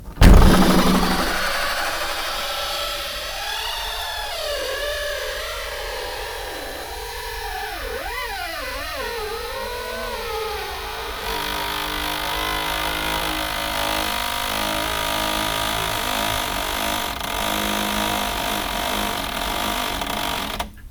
squeaky-door-open-slow-1.ogg